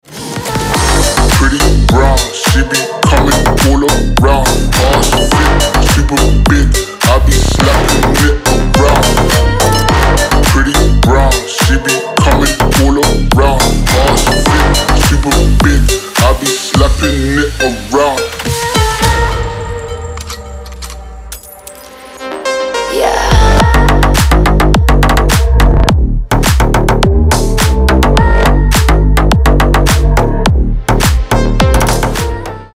• Качество: 320, Stereo
мужской голос
мощные басы
Bass House
качающие
Крутой качающий клубный трек